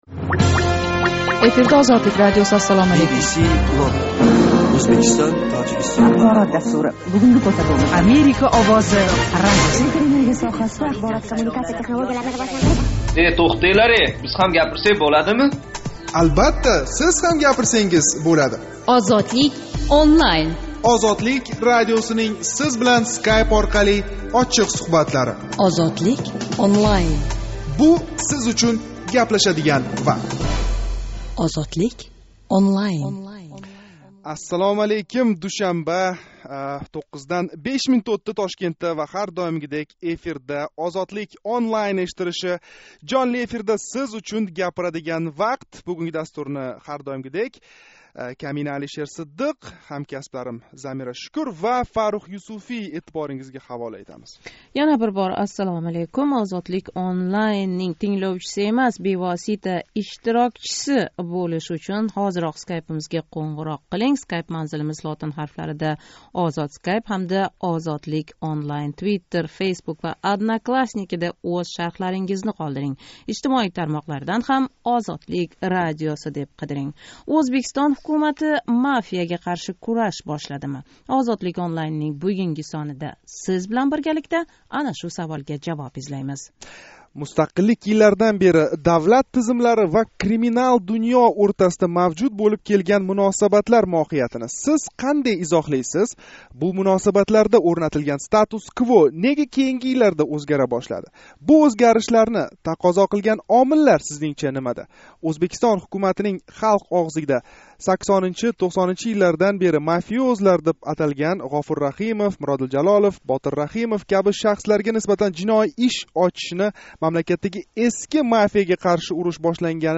Душанба¸ 11 март куни Тошкент вақти билан 21:05 да бошланадиган жонли¸ интерактив мулоқотимизда ўзбек мафияси “қирол”ларидан бири сифатида танилган Ғафур Раҳимовга нисбатан жиноий иш қўзғатилгани муносабати билан давлат тизимлари ва криминал дунë ўртасидаги алоқадорлик ҳақида гаплашамиз.